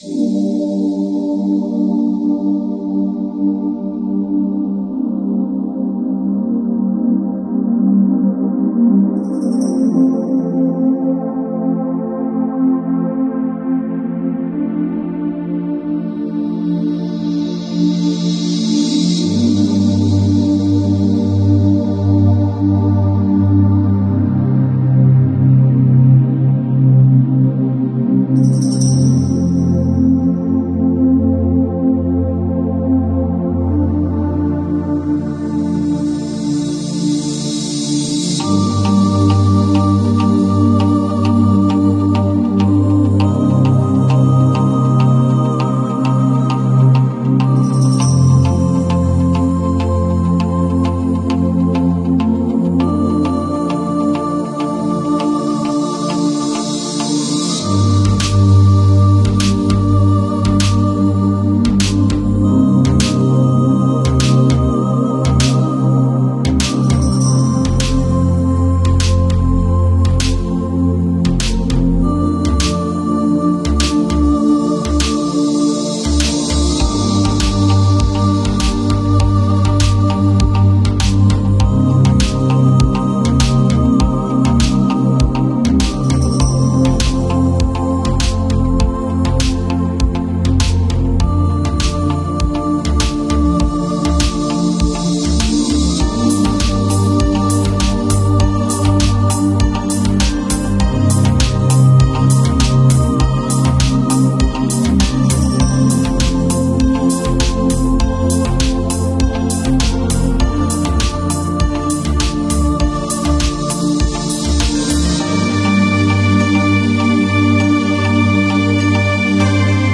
417 Hz release negative energy sound effects free download